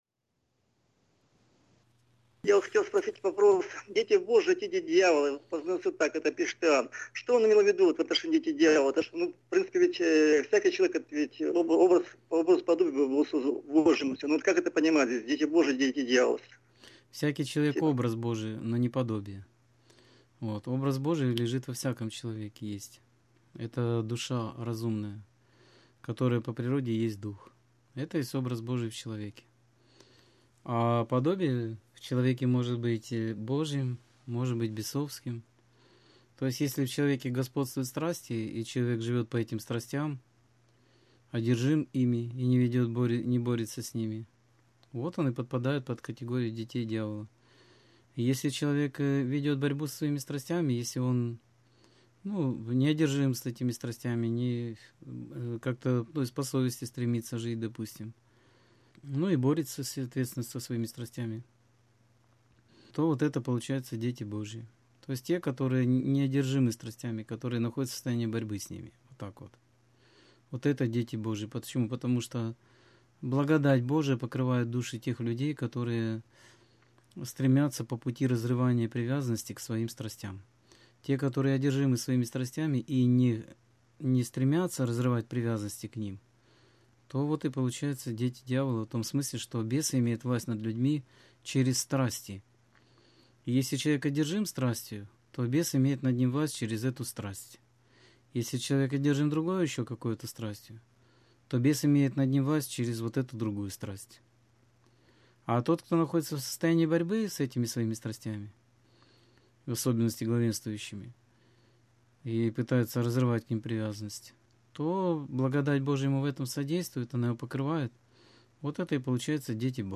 Скайп-беседа 20.08.2016